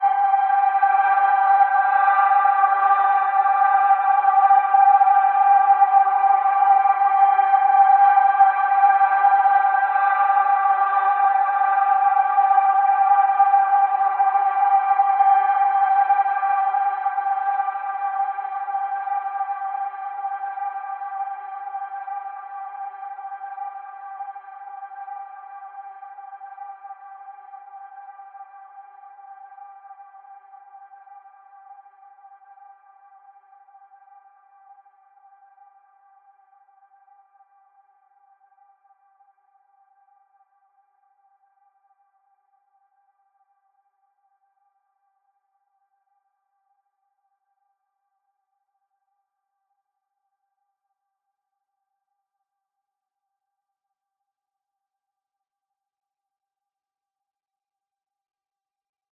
AV_Grave_Pad_C.wav